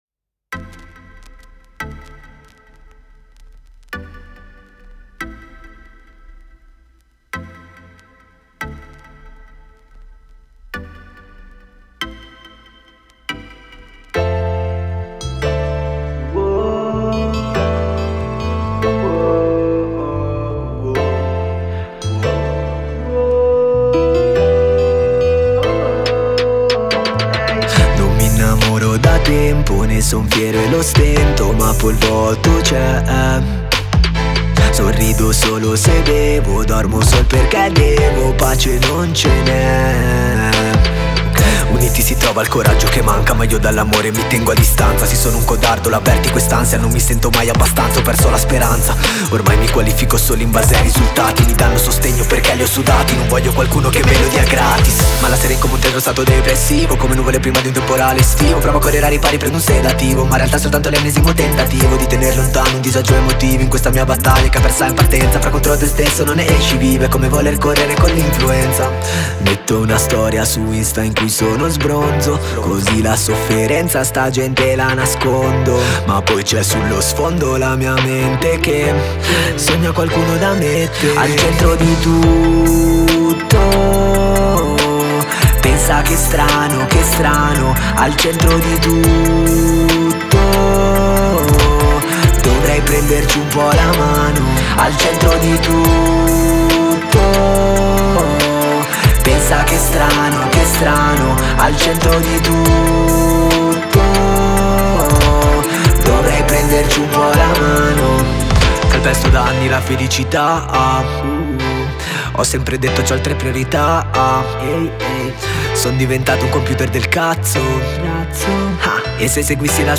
Genere: rap, trap